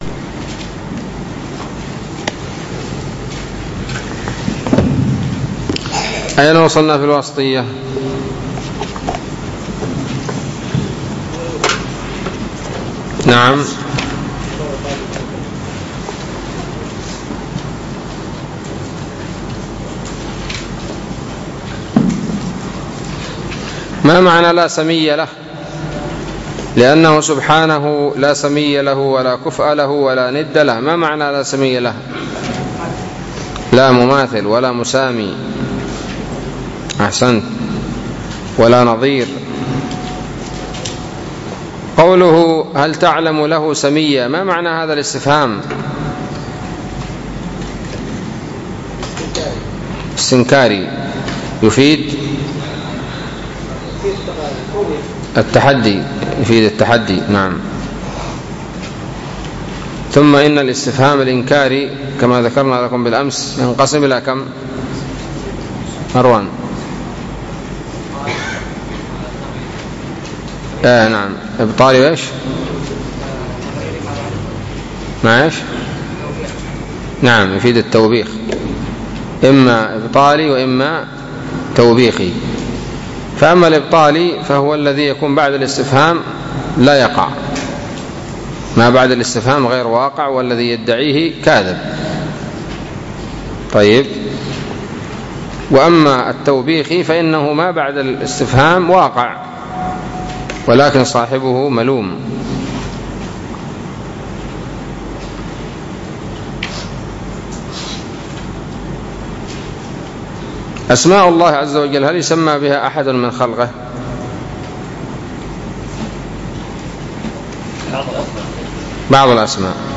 الدرس الرابع والعشرون من شرح العقيدة الواسطية